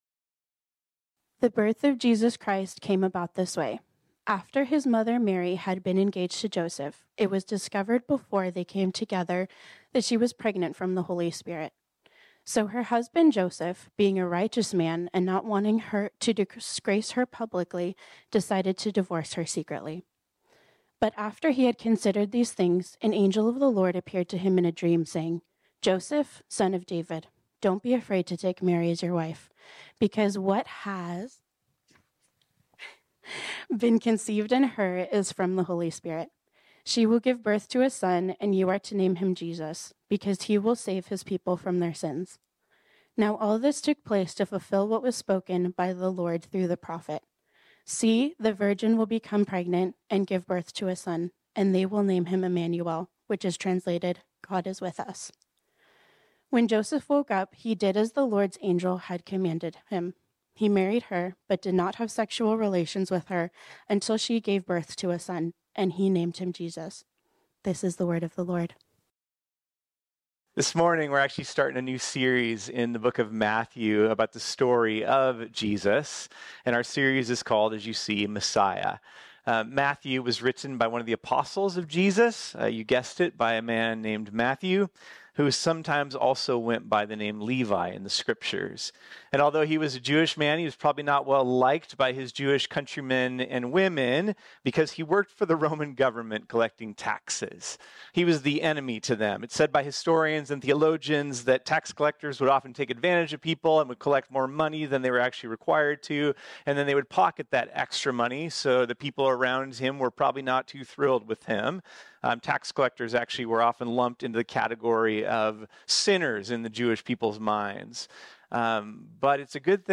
This sermon was originally preached on Sunday, December 17, 2023.